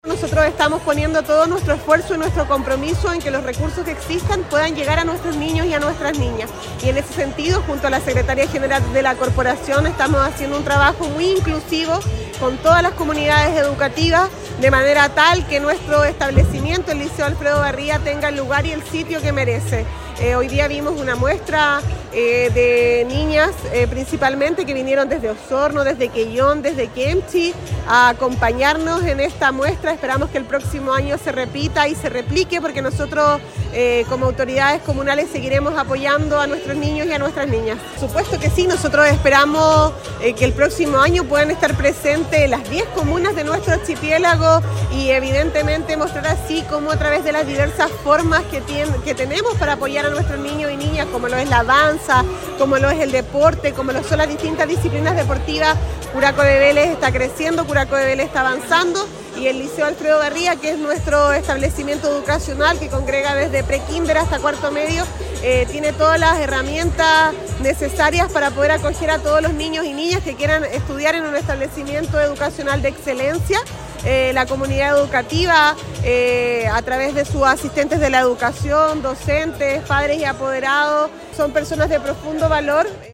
Al respecto, la alcaldesa Javiera Yáñez hizo un balance positivo de la jornada:
alcaldesa-encuentro-de-danza-.mp3